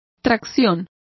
Complete with pronunciation of the translation of traction.